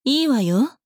大人女性│女魔導師│リアクションボイス
承諾・拒否